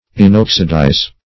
Search Result for " inoxidize" : The Collaborative International Dictionary of English v.0.48: Inoxidize \In*ox"i*dize\, v. i. To prevent or hinder oxidation, rust, or decay; as, inoxidizing oils or varnishes.
inoxidize.mp3